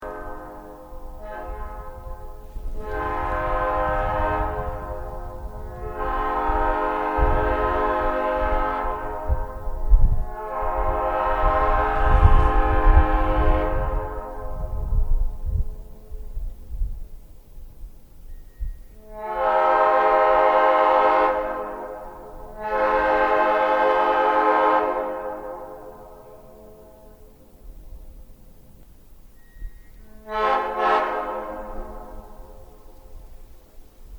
nathan airchime P5, P5A, P01235, P3, new cast, old cast, pat pending, pat pend, air horn, airhorn, railroad locomotive horn air chime
Sound Files of P horns: